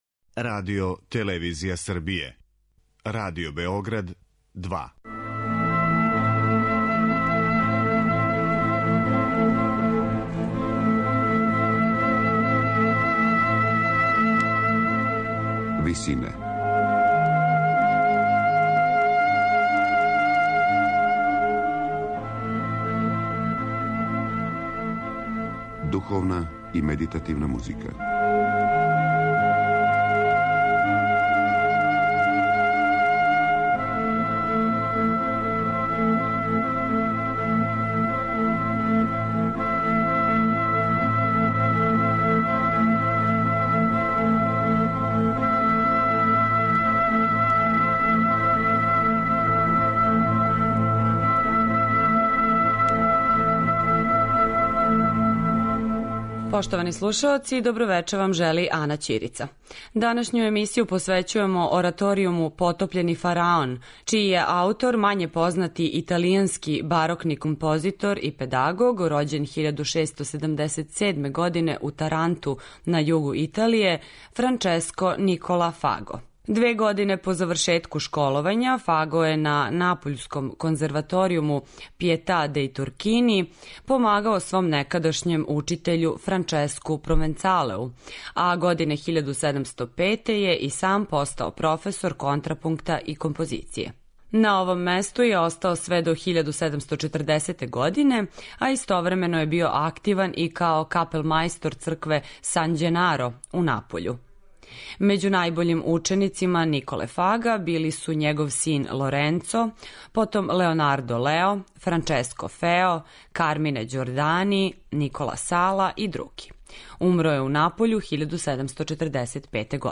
медитативне и духовне композиције
У првој овонедељној емисији која је посвећена духовној и медитативној музици, слушаћете одломке из ораторијума "Потопљени фараон" Франческа Николе Фага.